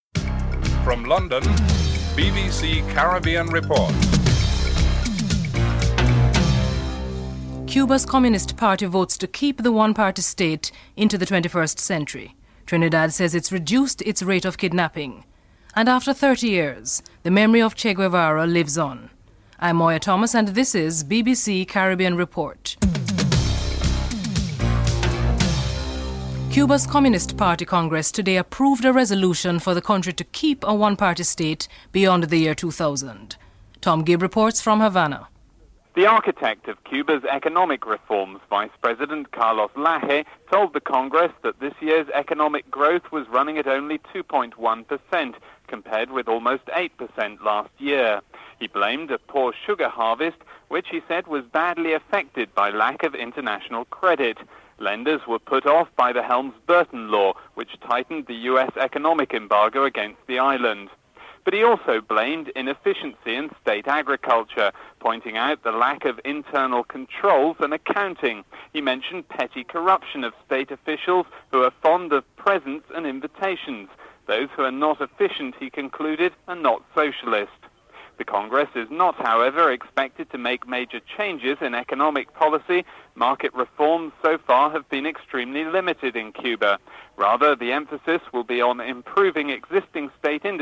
1. Headlines (00:00-00:25)
5. The Chairman of the Electoral Advisory Committee in Jamaica, William Chin Sue feels that electronic voting is out of the question for the upcoming poll (11:35-11:56)